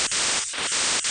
Преобразованная речь